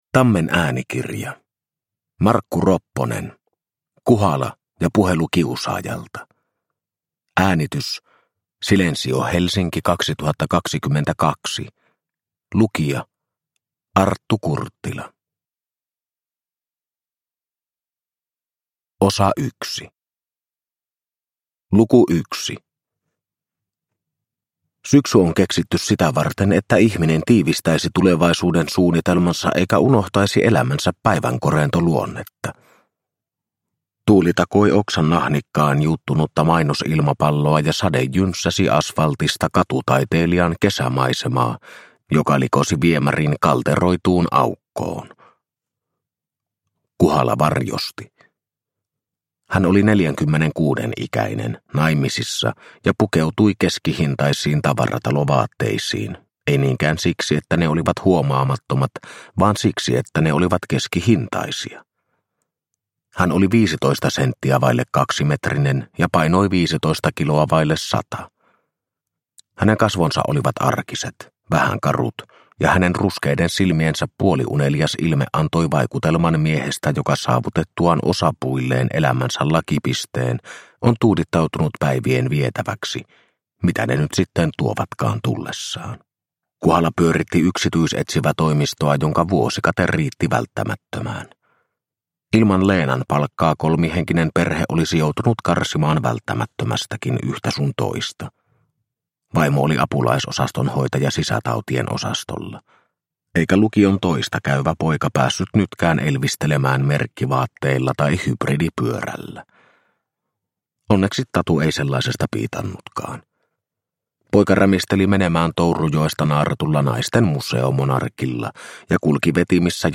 Kuhala ja puhelu kiusaajalta – Ljudbok – Laddas ner